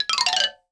52-prc01-bala-fx1.wav